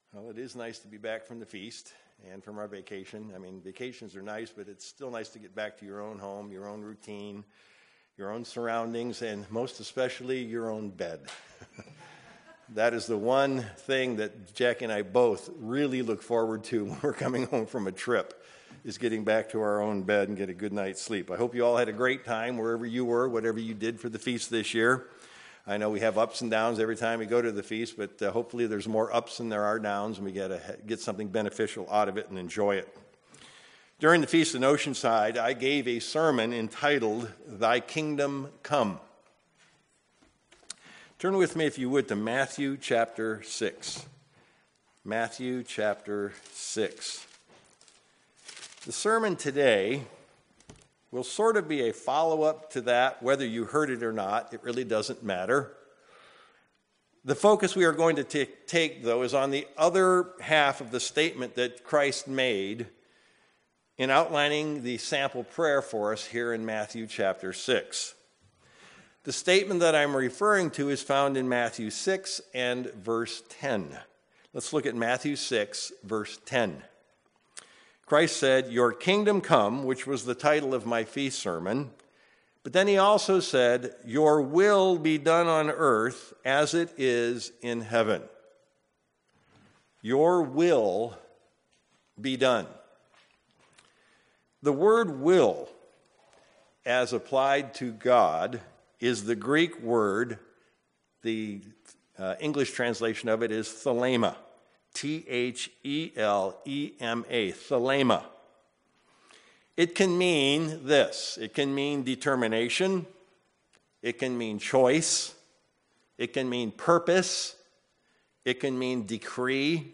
Given in Sacramento, CA
UCG Sermon Studying the bible?